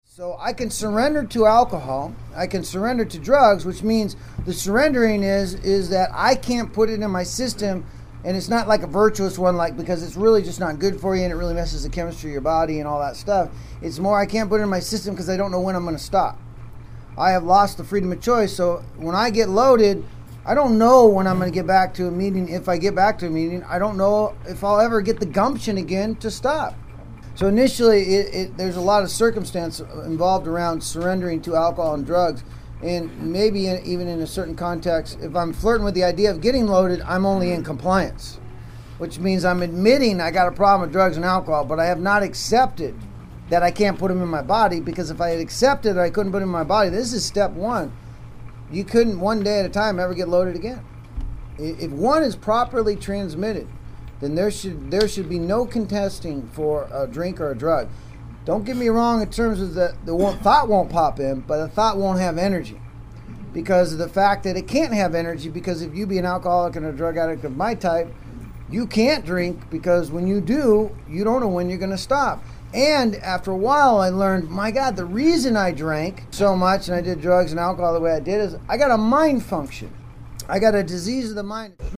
Within the lectures, you will hear people ask questions about why am I where I am, how can I get to a better place and what is blocking me.
A discussion about surrender, ego, and self-centered thinking in recovery, showing how addictionism continues to affect daily life even in sobriety, and how the steps offer a path to real change.